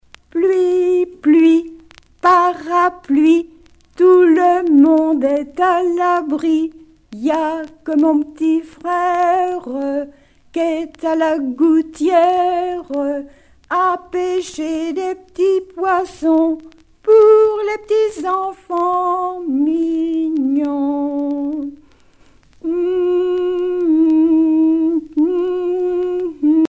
Thème : 0080 - L'enfance - Enfantines diverses
Genre brève
Catégorie Pièce musicale éditée